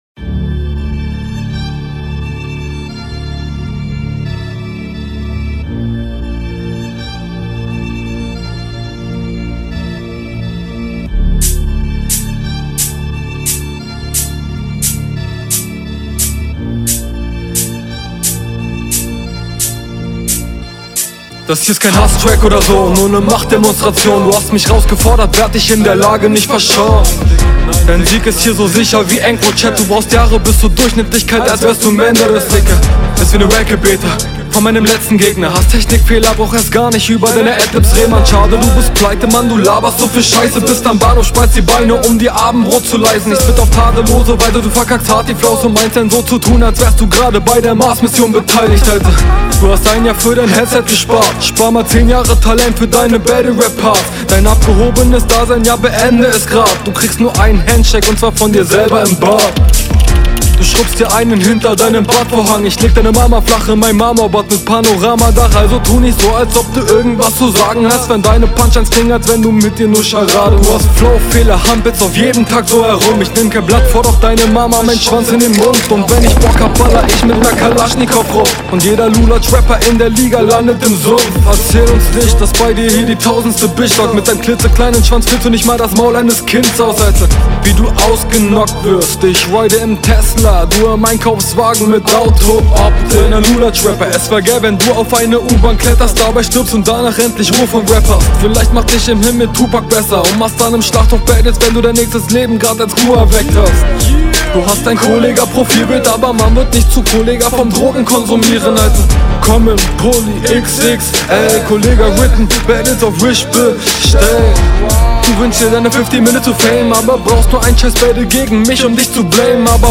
Mische hier etwas schwächer als vorher, liegt aber vllt auch an der länge und dem …
mag so violin type beats persönlich nich besonders ^^ ancrochat line ist cool. wish bestellt …